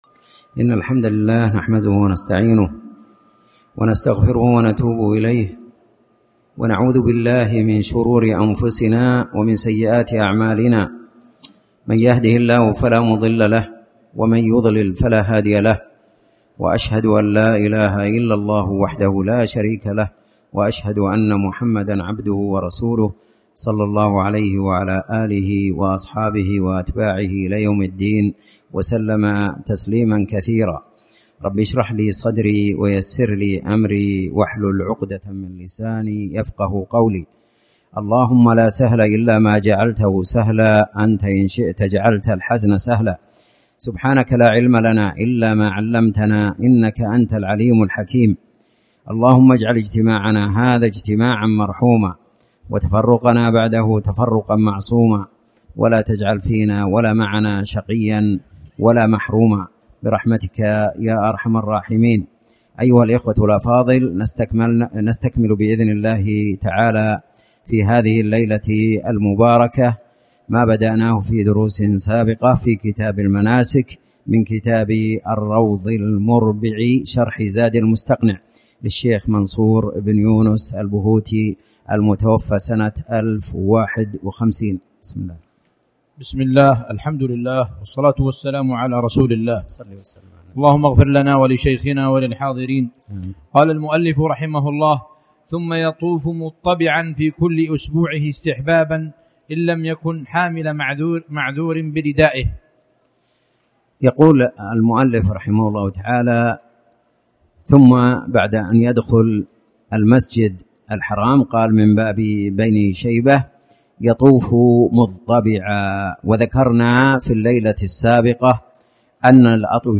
تاريخ النشر ٢٠ محرم ١٤٣٩ هـ المكان: المسجد الحرام الشيخ